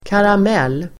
Uttal: [karam'el:]